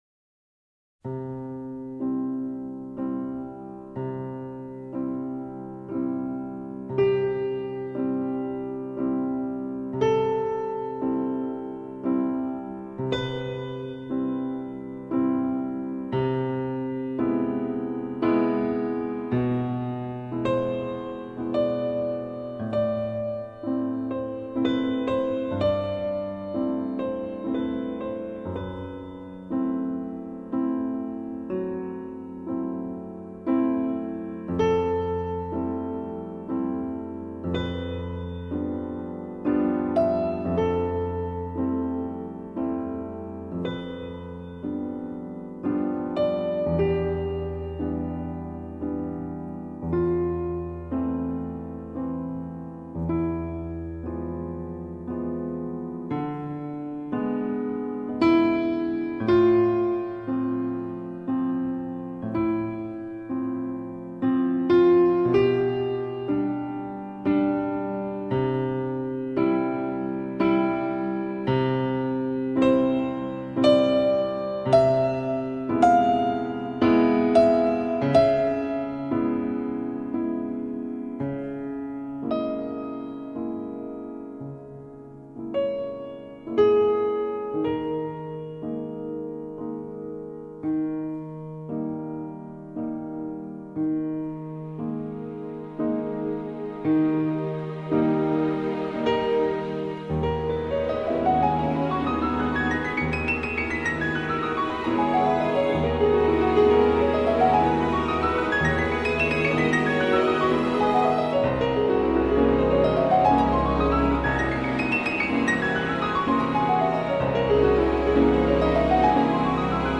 畅销日本十余年的治疗系钢琴音乐
优美的旋律与透过冥想感知的灵气